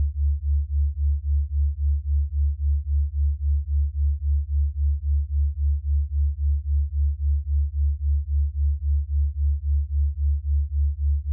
Below are two wav files containing two tones of slightly different frequency. The first file puts each tone in a separate channel so that one tone is heard only by the right ear and the other tone only by the left.
One tone in either ear, beats constructed by brain